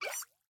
Minecraft Version Minecraft Version 25w18a Latest Release | Latest Snapshot 25w18a / assets / minecraft / sounds / mob / axolotl / idle_air2.ogg Compare With Compare With Latest Release | Latest Snapshot
idle_air2.ogg